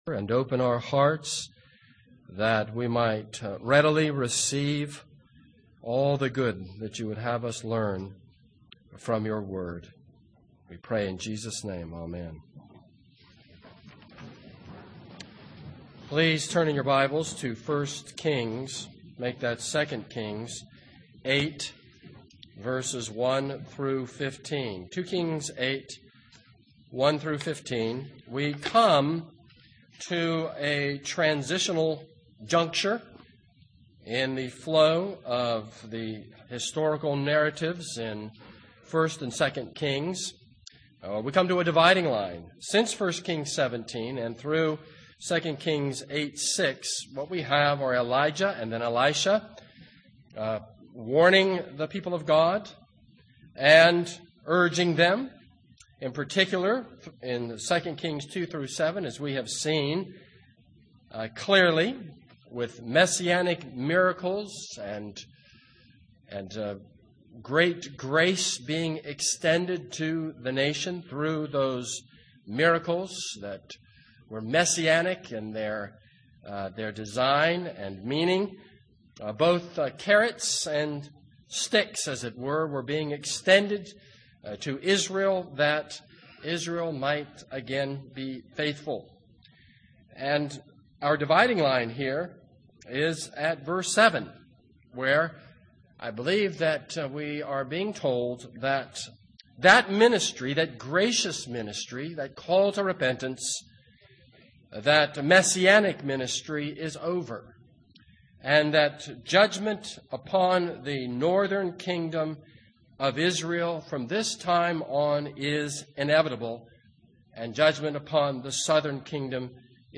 This is a sermon on 2 Kings 8:1-15.